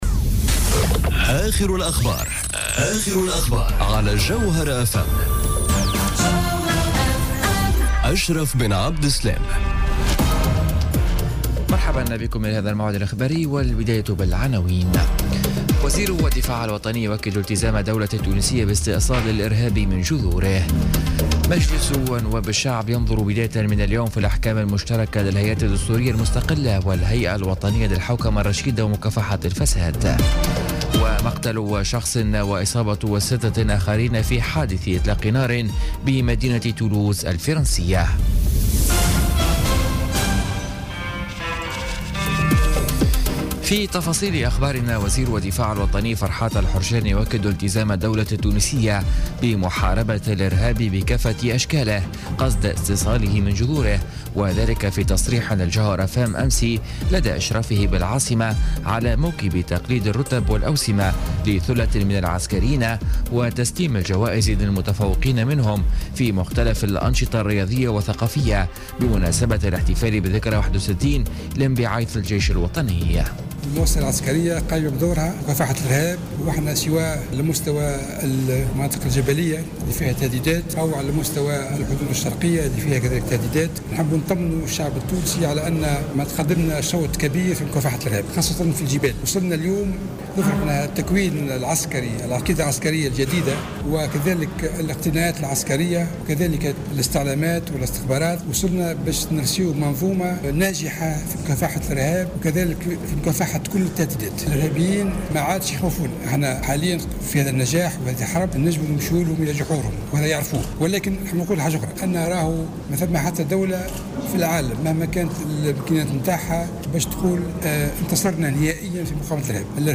نشرة أخبار منتصف الليل ليوم الثلاثاء 4 جويلية 2017